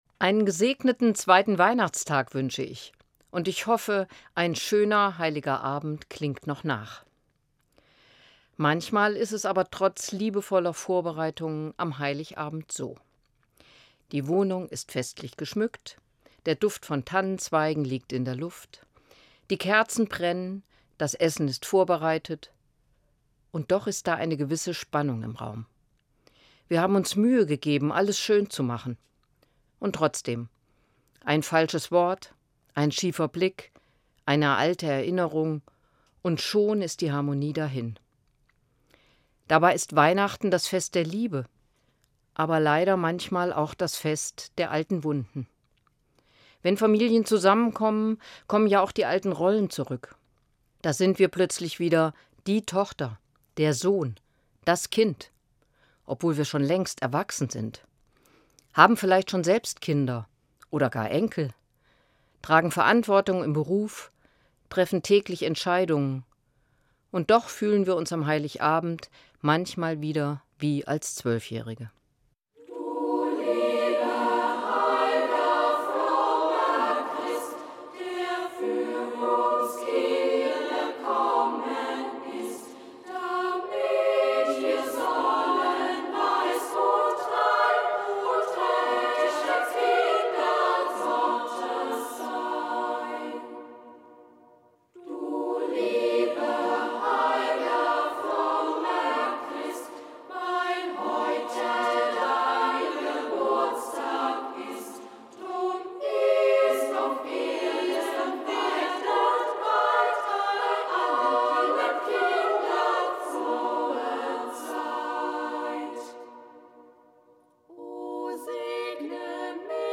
Eine Sendung von Sabine Kropf-Brandau, Evangelische Pröpstin, Sprengel Hanau-Hersfeld